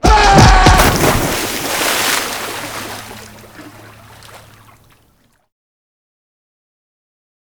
poolpush.wav